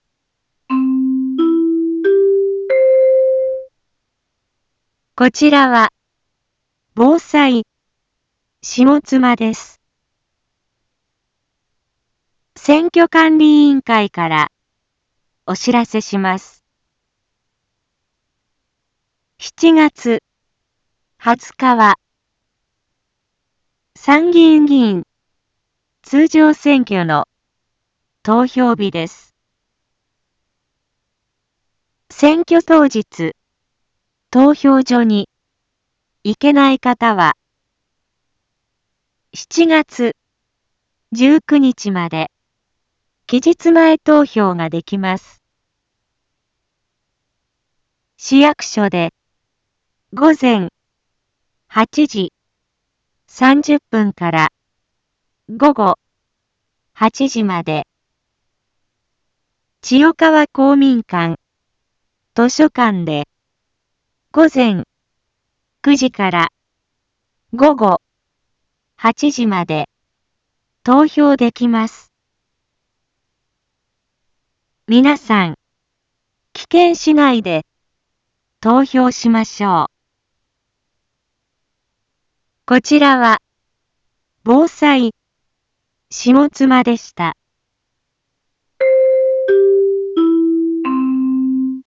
一般放送情報
Back Home 一般放送情報 音声放送 再生 一般放送情報 登録日時：2025-07-19 13:01:51 タイトル：参議院議員通常選挙の啓発（期日前投票） インフォメーション：こちらは、ぼうさいしもつまです。